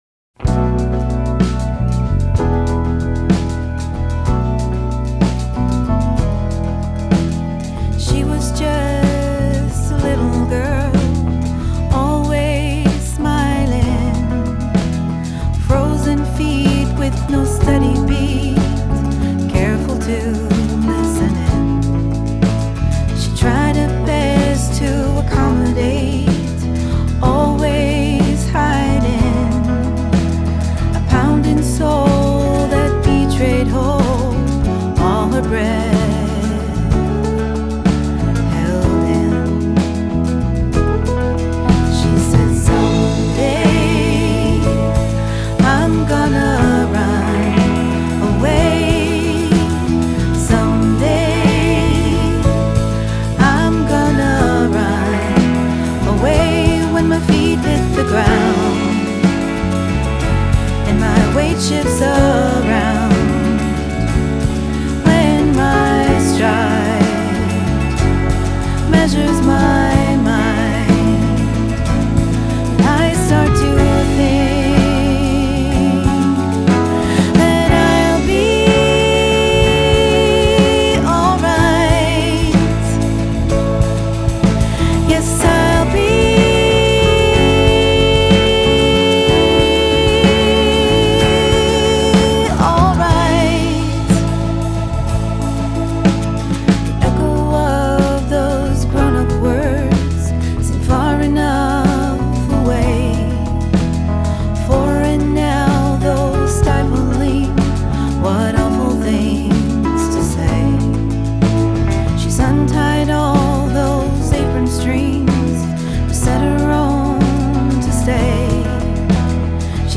• Singer-songwriter
• Close mics only, dry/muffled drum tuning